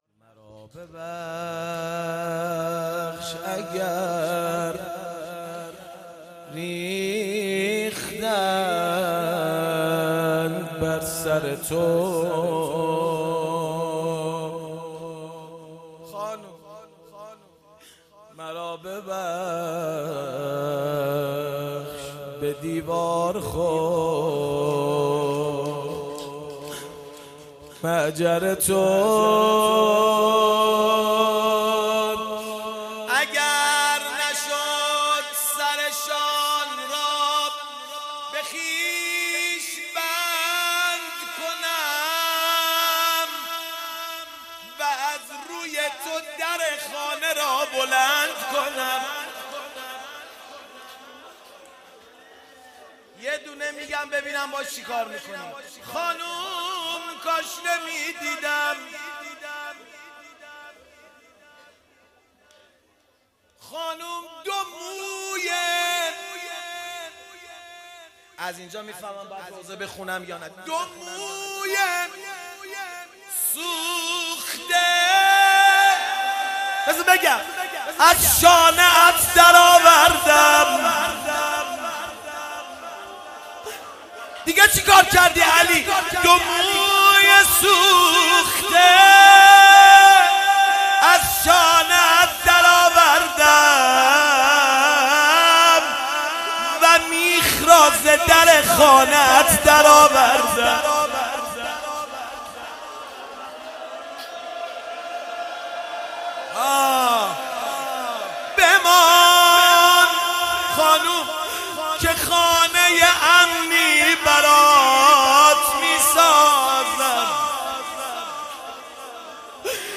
فاطمیه97 - حیدریون اصفهان - روضه